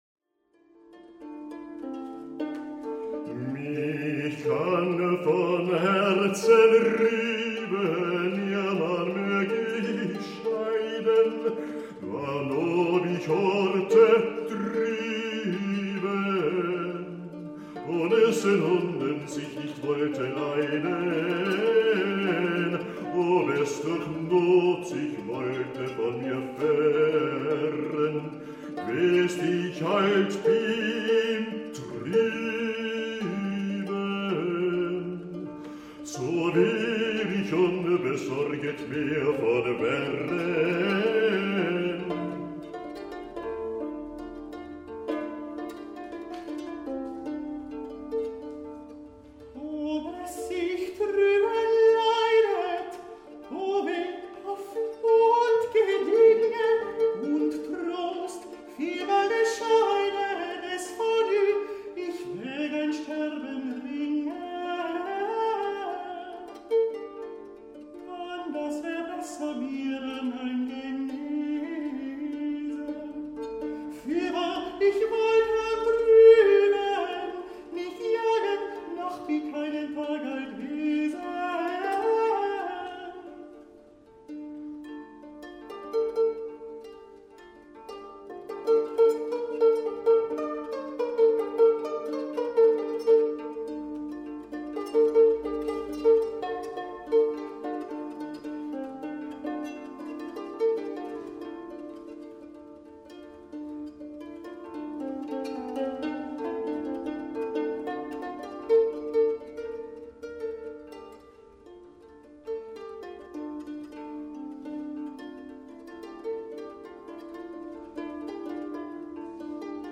MEDIEVAL TRIO I and II / Music of the Middle Ages between Christianity and Islam: hurdy-gurdy and voice added, two different programmes depending on the casting.